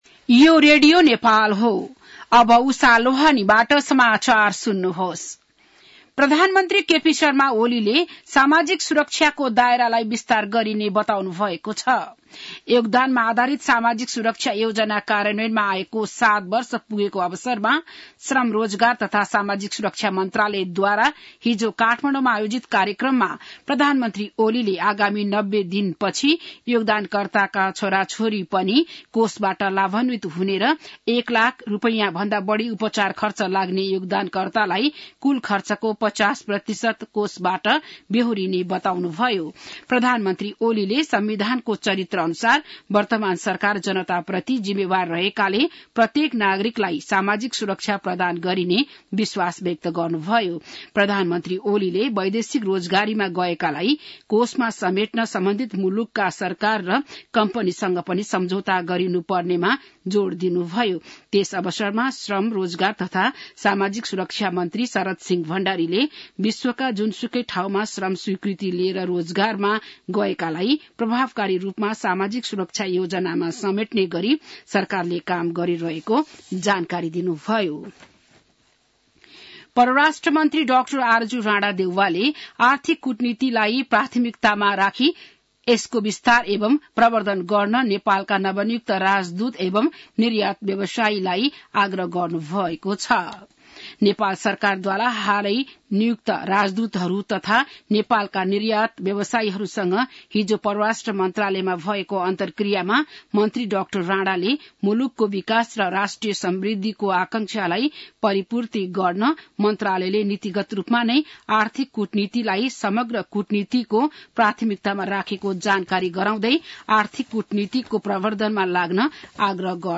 बिहान १० बजेको नेपाली समाचार : १३ मंसिर , २०८१